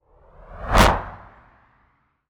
bullet_flyby_designed_05.wav